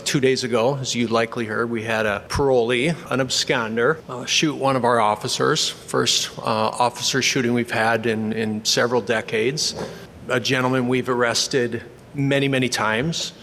Governor Larry Rhoden’s “Project Prison Reset” Task force is receiving public comments Thursday.
Sioux Falls Mayor Paul TenHaken started things off, saying it’s long past the time to take action…and noting that many parolees end up in Sioux Falls…including some who are dangerous.
Prison-Testimony-TenHaken.mp3